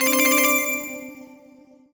collect_item_jingle_04.wav